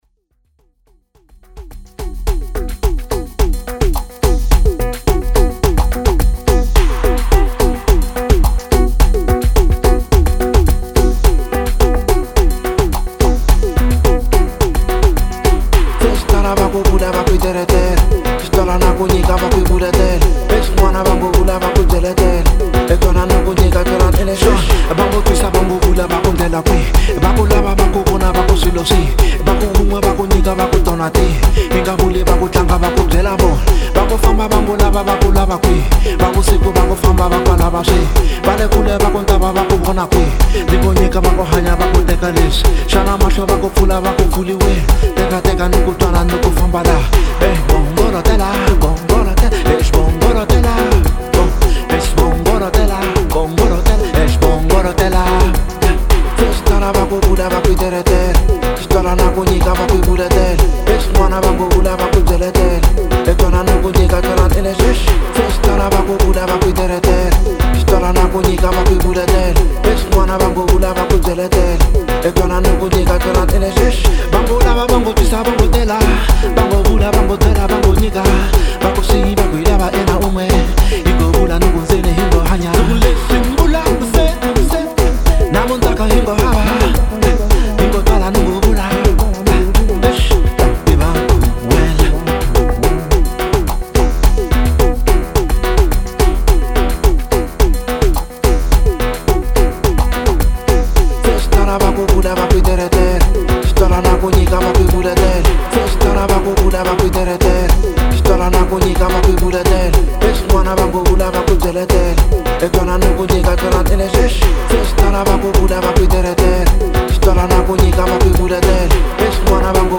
02:34 Genre : Hip Hop Size